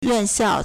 院校 yuànxiào
yuan4xiao4.mp3